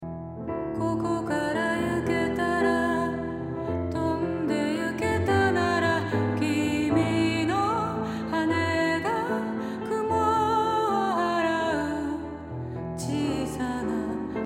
リバーブのみ
ボーカルだけが大きなスペースに配置されて少し遠くで歌われているように感じますね。